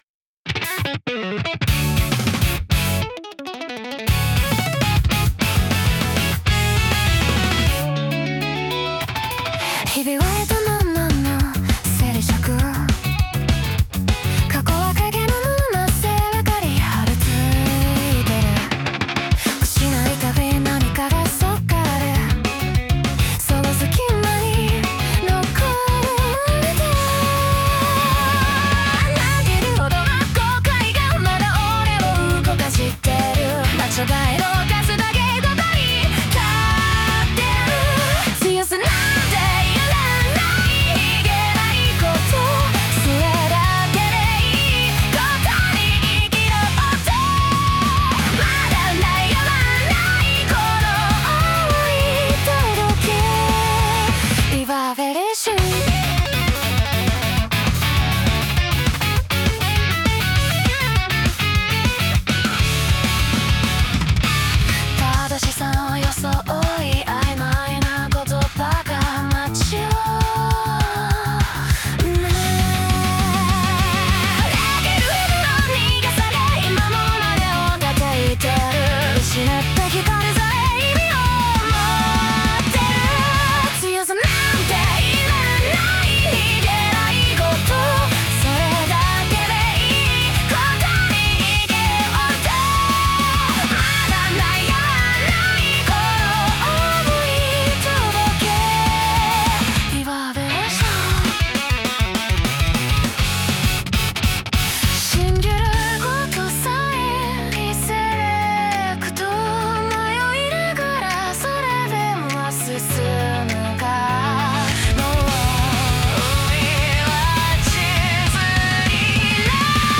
女性ボーカル
イメージ：J-ROCK,女性ボーカル,かっこいい,前向き,切ない,シューゲイザー,リヴァーベレーション